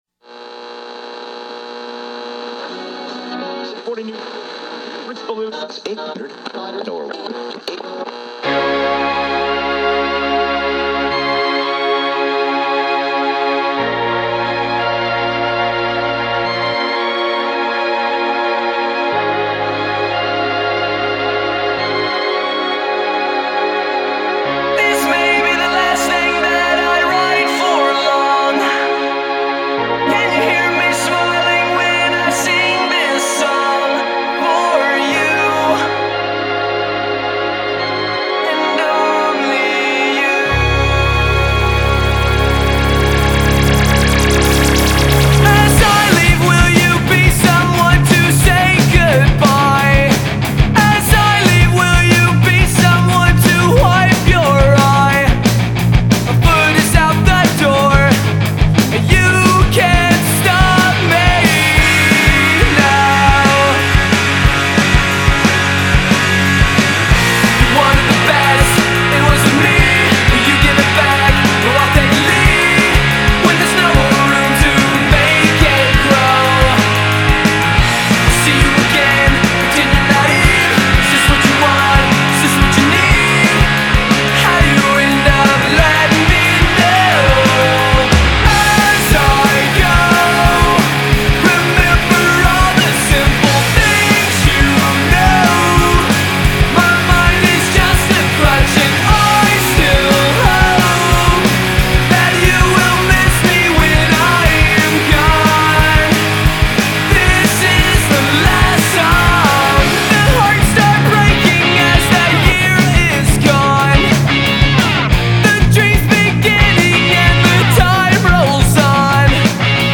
Тема: поп-рок